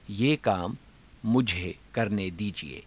ゆっくり ふつう